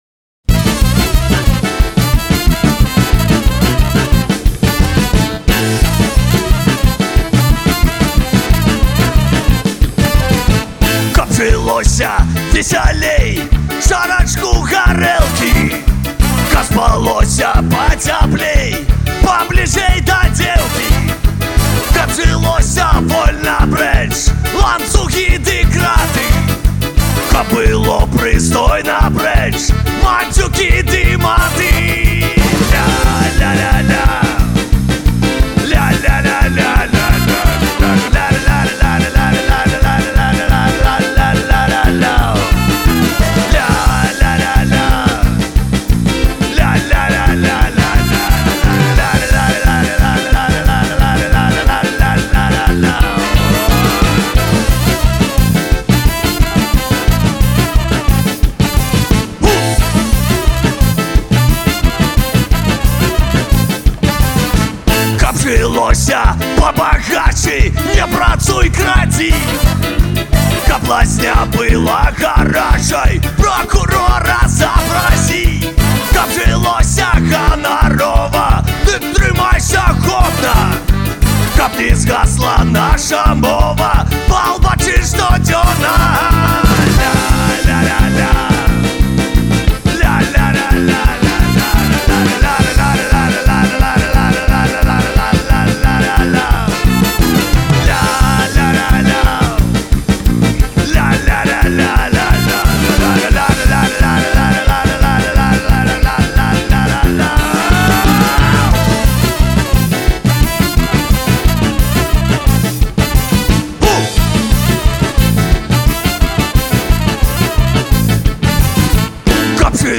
Фольк-панк гурт заснаваны ў 2012 ў Горадні.
джазавую-вэрсію